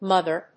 音節múg・ger 発音記号・読み方
/ˈmʌgɝ(米国英語), ˈmʌgɜ:(英国英語)/